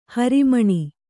♪ hari maṇi